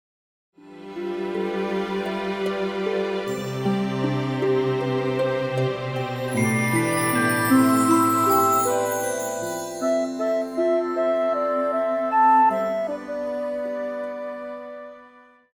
流行
高音直笛
管弦樂團
演奏曲
世界音樂
獨奏與伴奏
有主奏
有節拍器